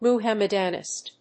音節Mu・hám・mad・an・ìsm 発音記号・読み方
/‐dənìzm(米国英語)/
音節Mu･ham･mad･an･ism発音記号・読み方mʊhǽmədənɪ̀z(ə)m